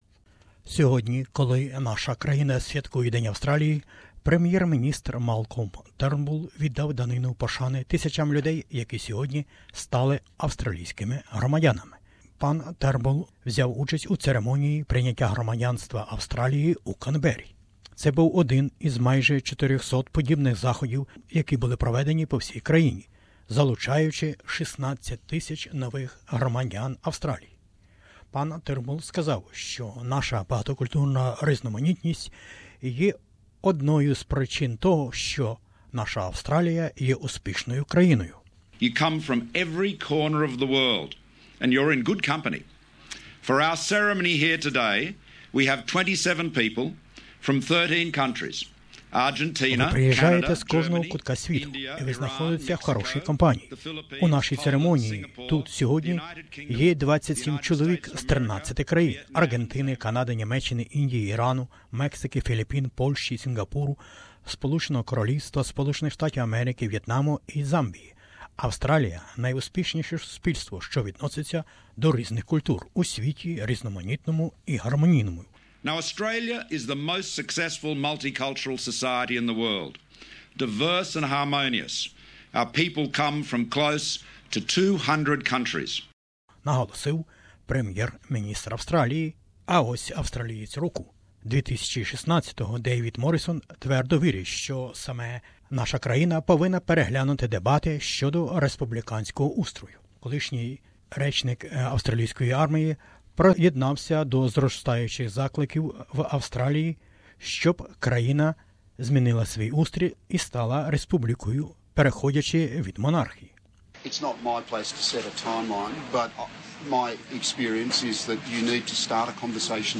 Prime Minister Malcolm Turnbull has paid tribute to thousands of people that have today become Australian citizens. Mr Turnbull made the remarks when he presided over an Australia Day citizenship ceremony in Canberra.